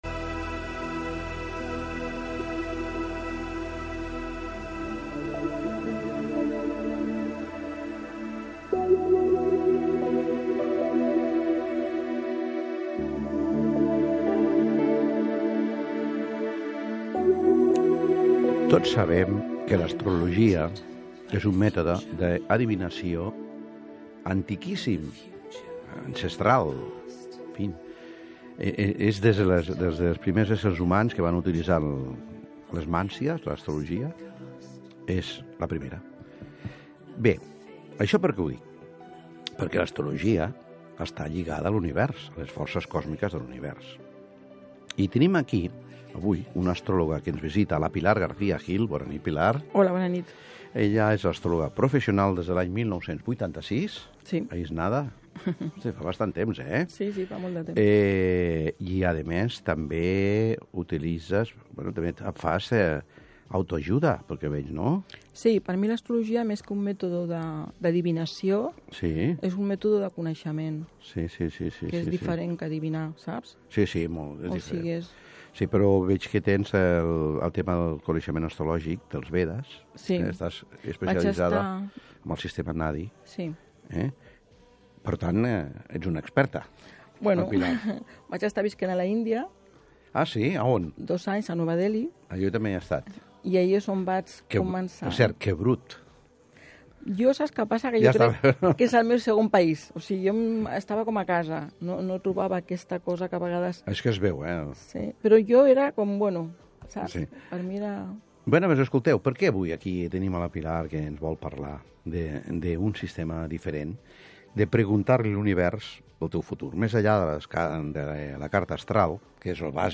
Programa de Radio RAC1.